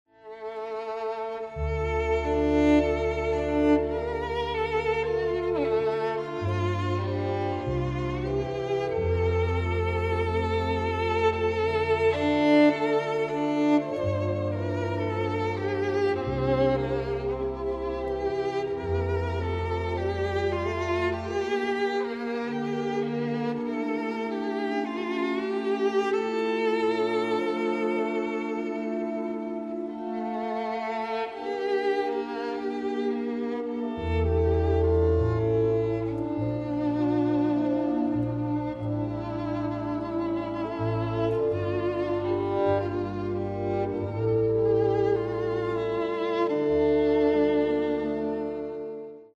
Tags: botones programa radio fatality la nave